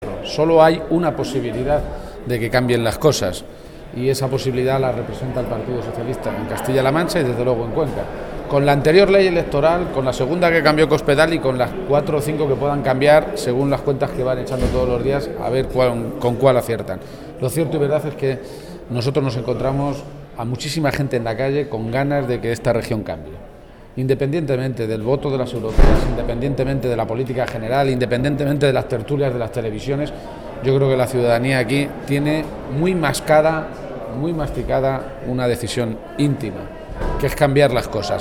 García-Page se pronunciaba de esta manera esta mañana, en Cuenca en una comparecencia ante los medios de comunicación minutos antes de que comenzara la reunión de un Comité Provincial extraordinario del PSOE de esa provincia.
Cortes de audio de la rueda de prensa